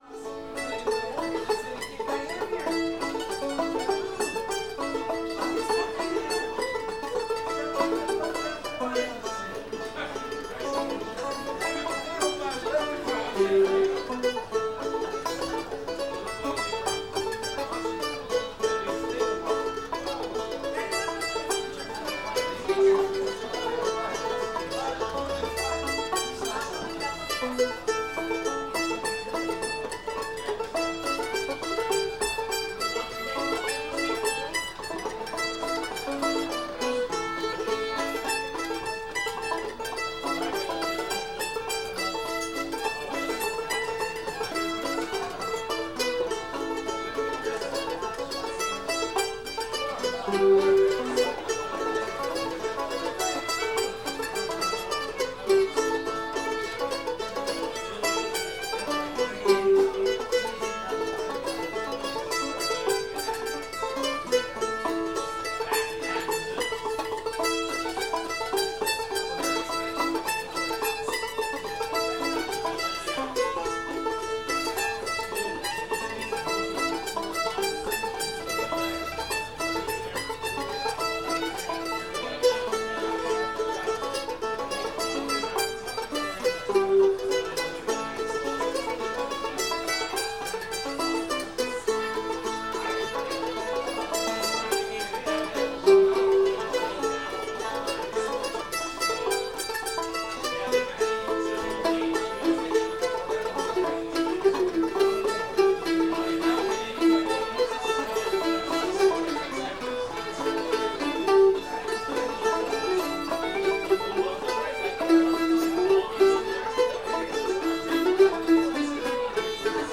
lady of the lake [A modal]